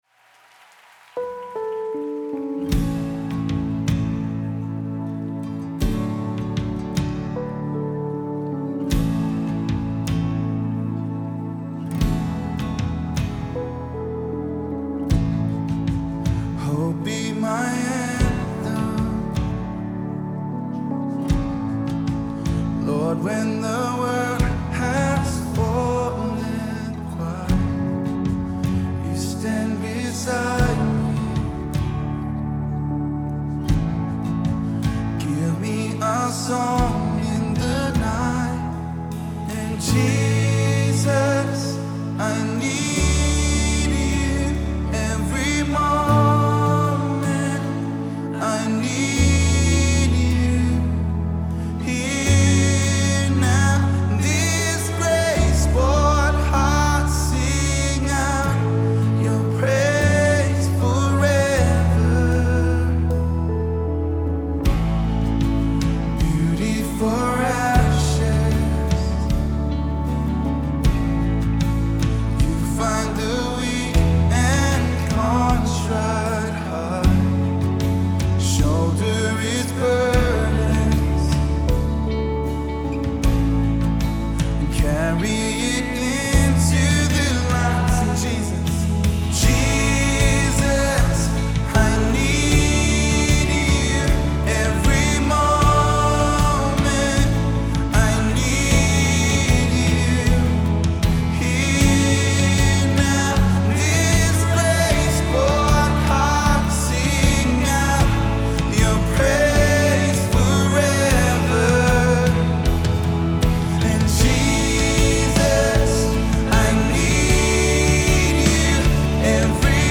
gospel song
praise & worship group